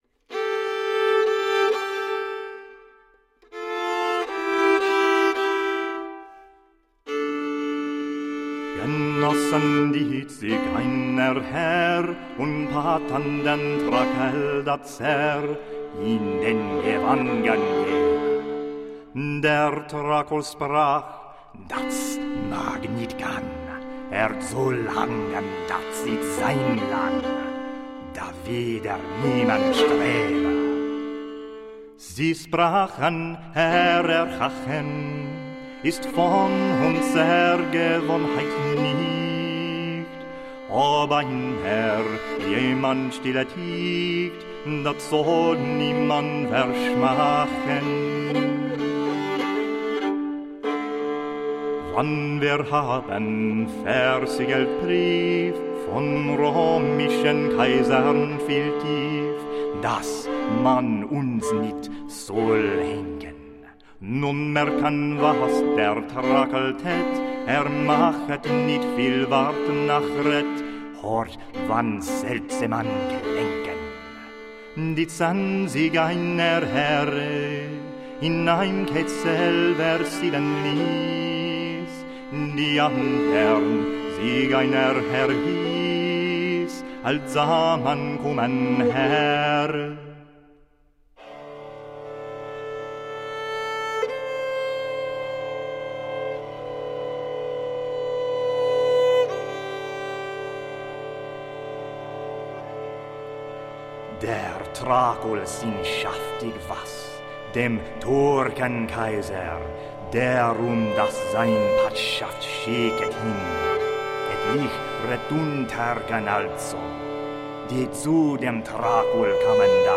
Medieval music from the 12th to the 15th centuries.